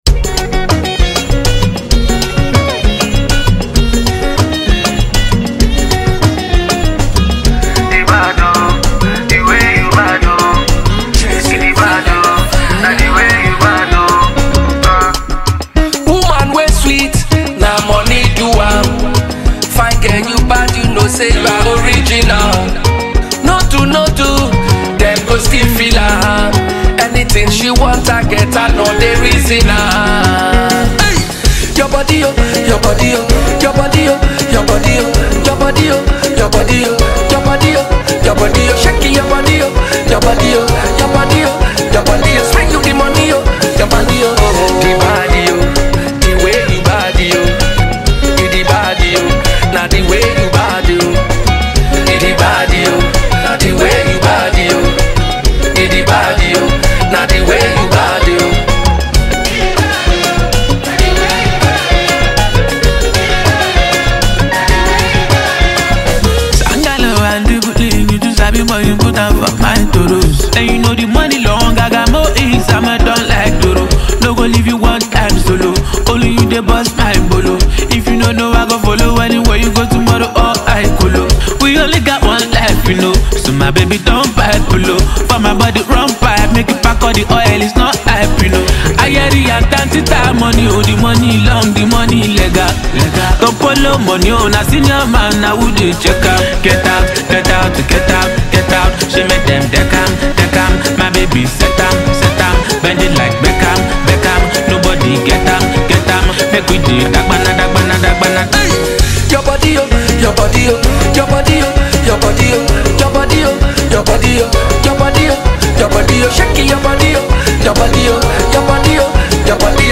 The energy on this track is immediate and hard to ignore.